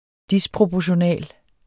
Udtale [ ˈdispʁobʌɕoˌnæˀl ]